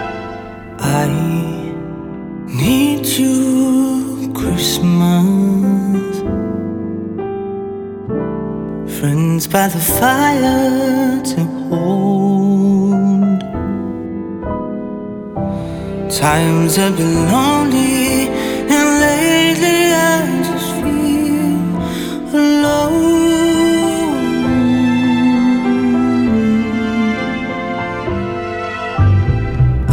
• Holiday
Christmas song